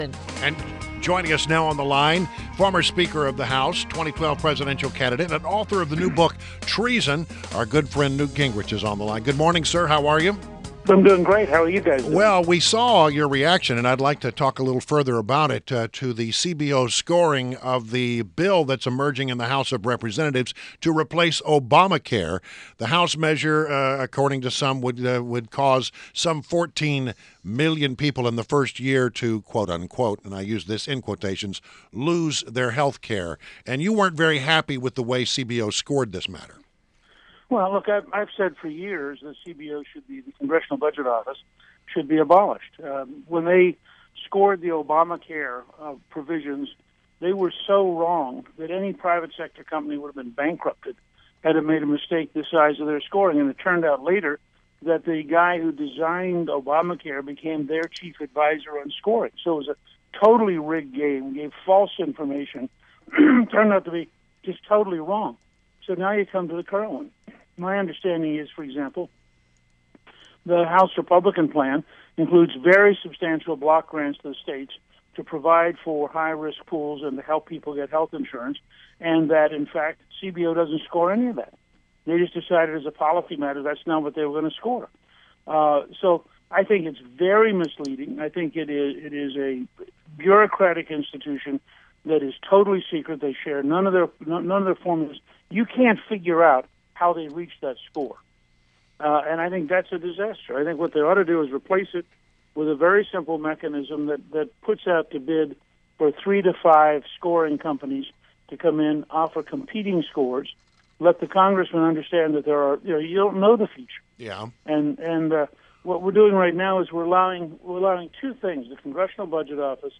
WMAL Interview - NEWT GINGRICH - 03.15.17